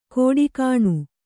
♪ kōḍi kāṇu